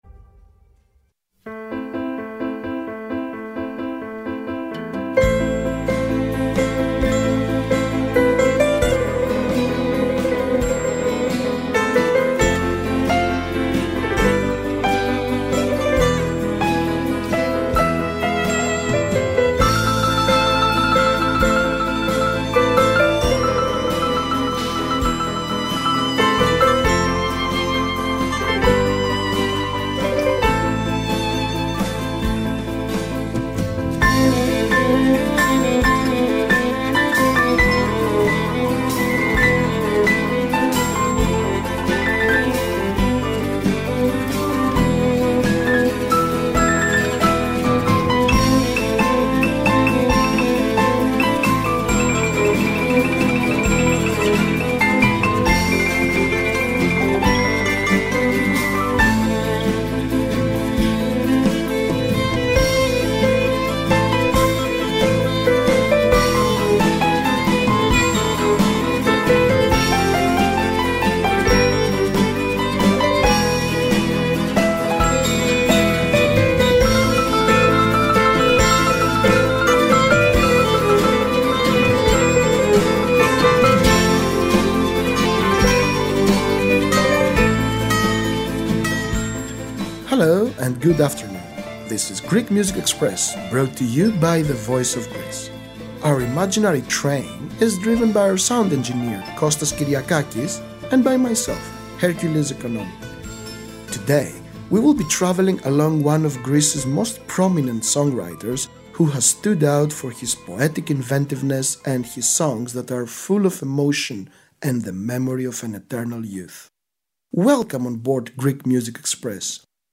A radio broadcast in English brought to you by the Voice of Greece.